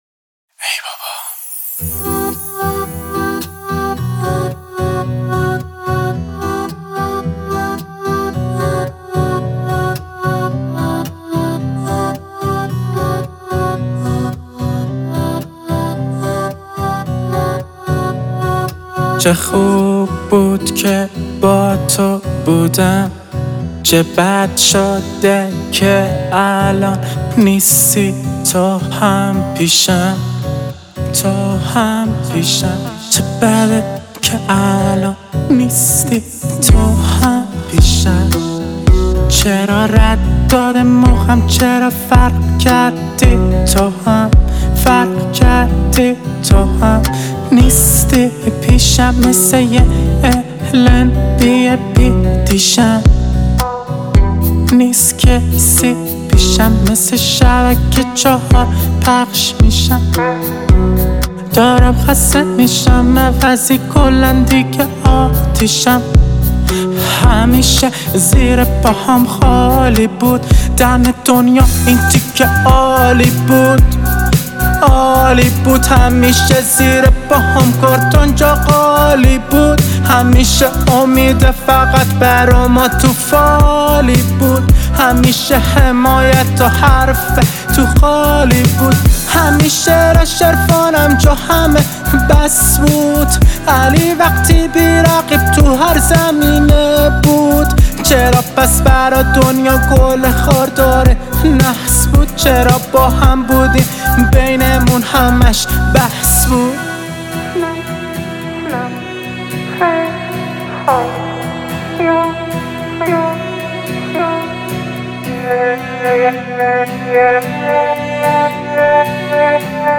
آهنگ رپ